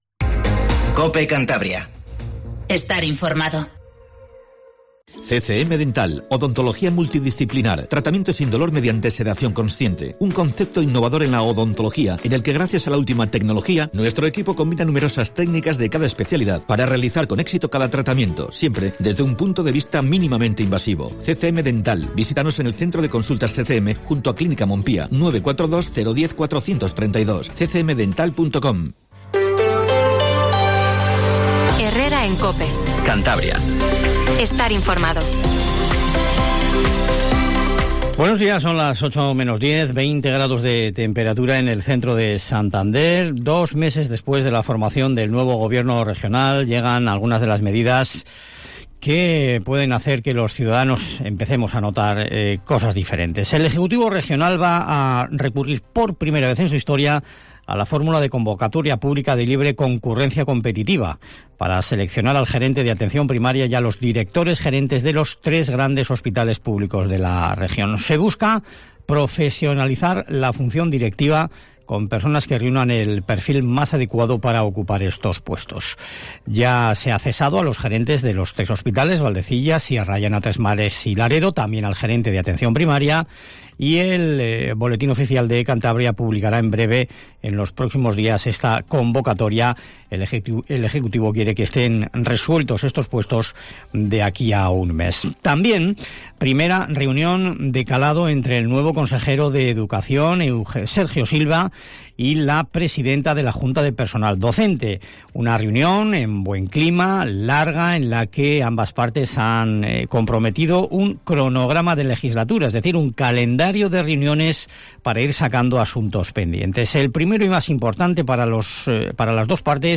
Informativo Matinal Cope 07:50